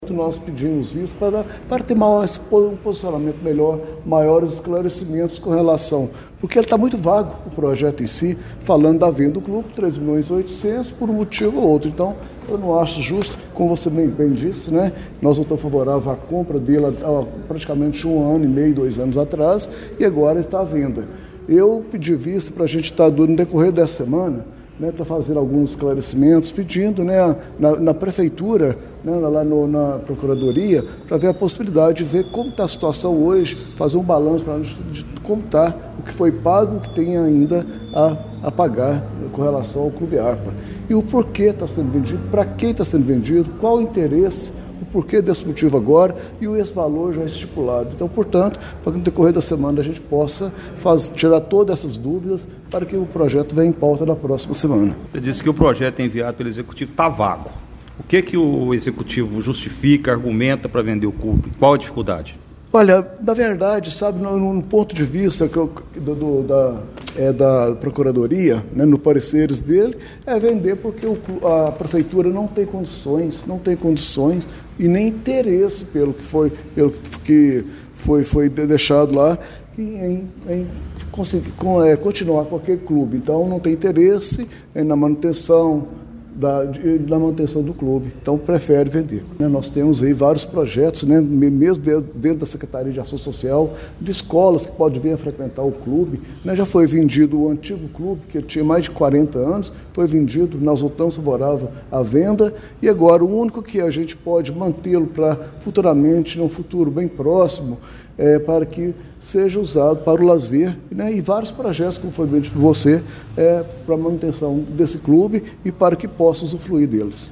Durante a sessão realizada nesta terça-feira (07), o Projeto de Lei Ordinária nº 15/2026, que autoriza a prefeitura a vender o imóvel do extinto Clube Arpa, teve sua tramitação interrompida após um pedido de vista, revelando profundas divergências entre os parlamentares sobre o destino da área.